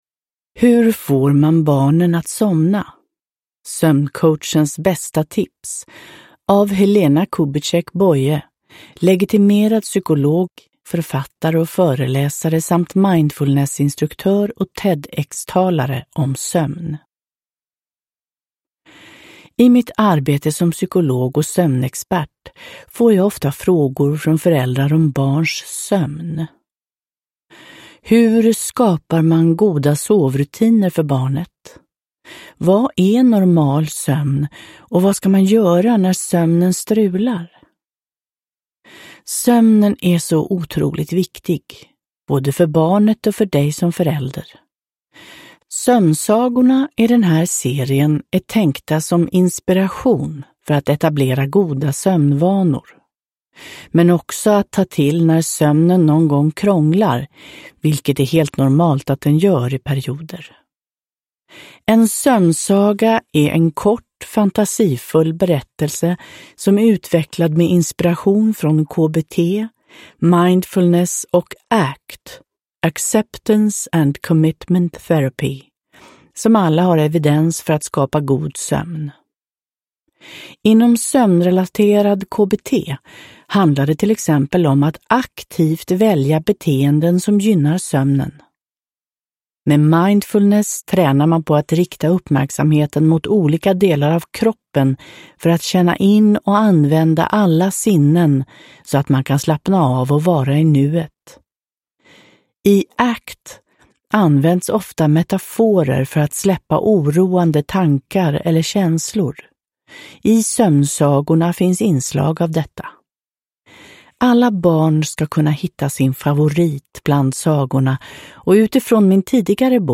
Sov gott! Om barns sömn – Ljudbok – Laddas ner